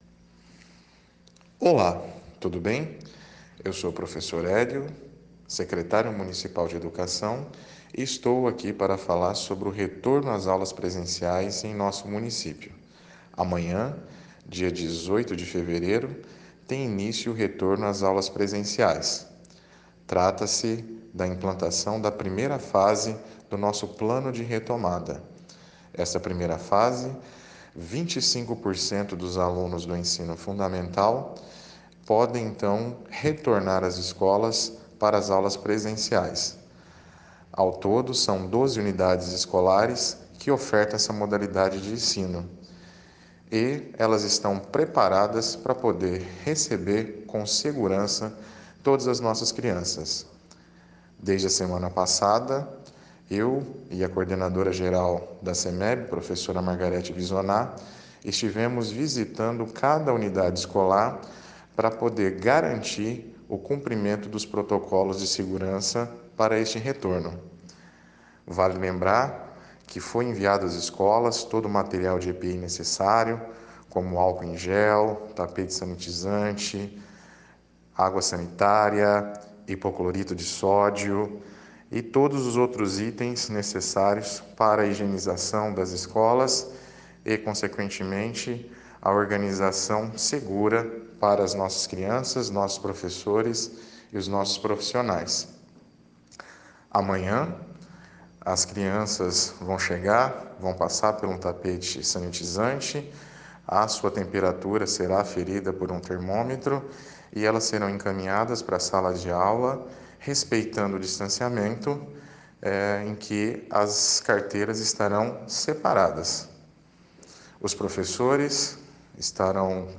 O secretário da Educação, Hélio Souza, fala dos últimos preparativos para o retorno gradual das aulas presenciais em Bebedouro, em 18 de fevereiro.
Ouça aqui o áudio do secretário de Educação, Hélio Souza.